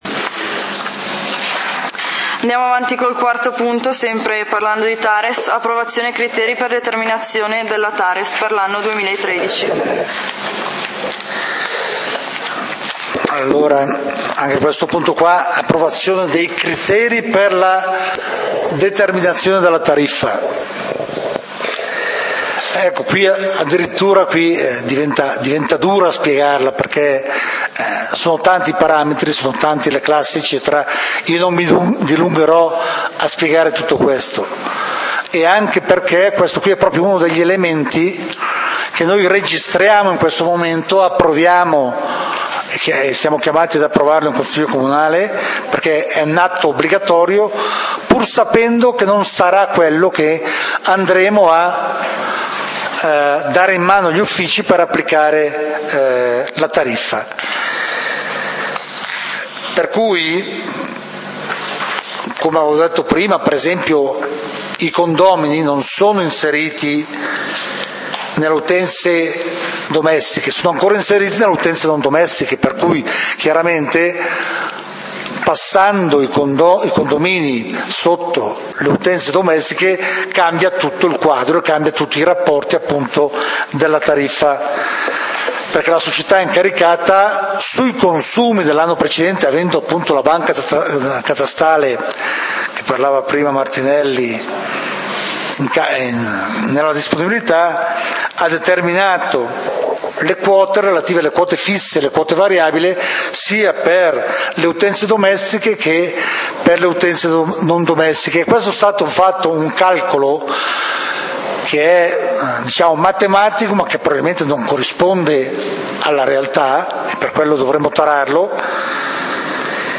Punti del consiglio comunale di Valdidentro del 01 Agosto 2013